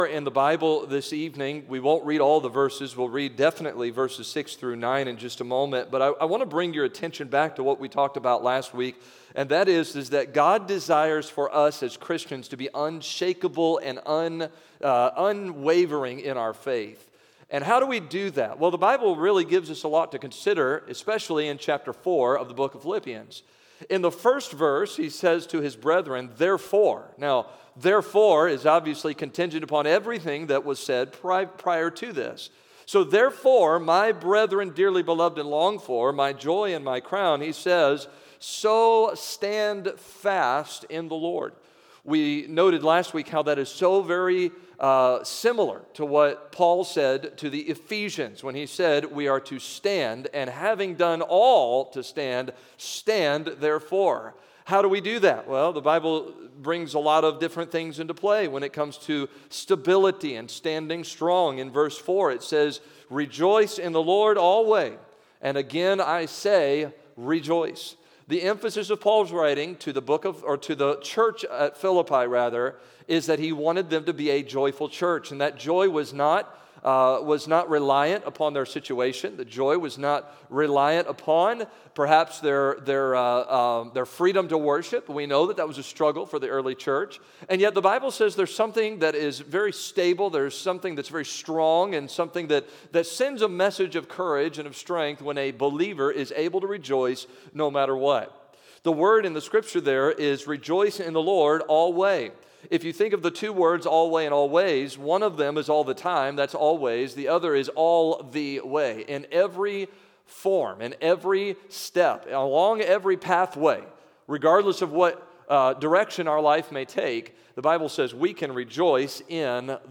The Battlefield of the Mind - FaithWay Baptist Church
October 2022 Sunday Evening Scripture: Phil 4 Download: Audio